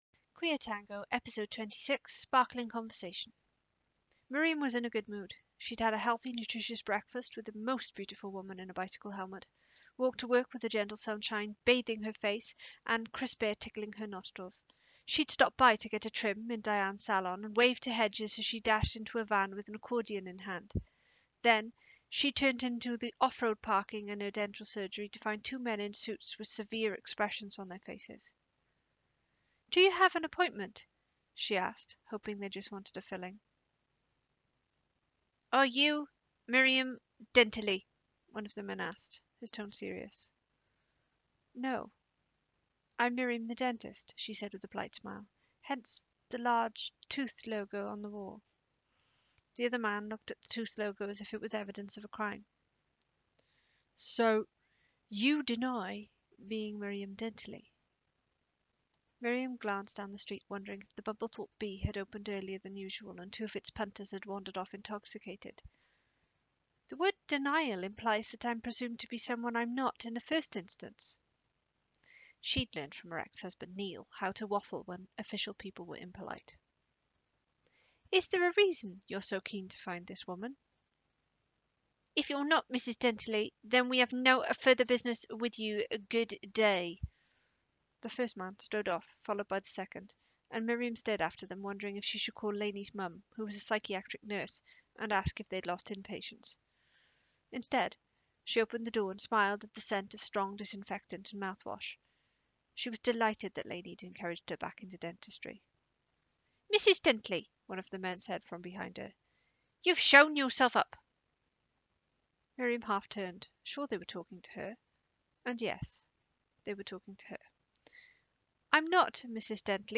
I hope you enjoy the accents, the many bloopers and typos as much as the story as here is this week’s Queer Tango.